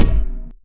chime8.ogg